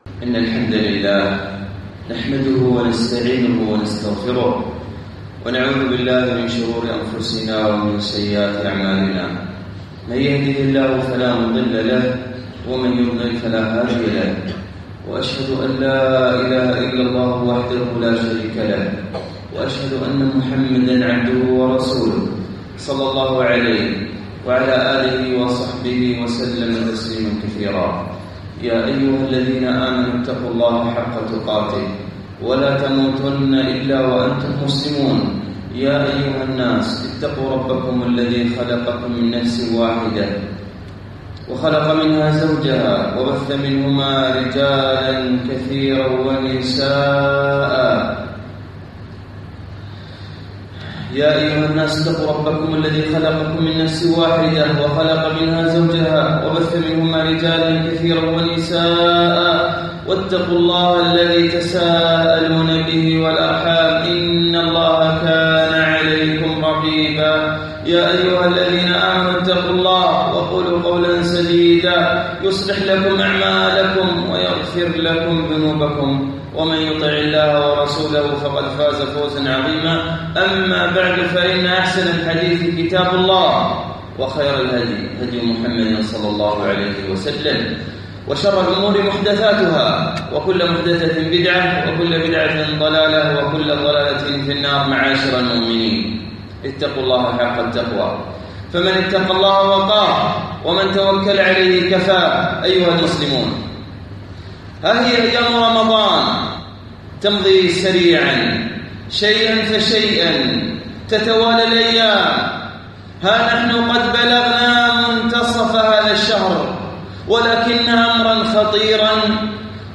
خطبة بعنوان